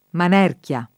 [ man $ rk L a ]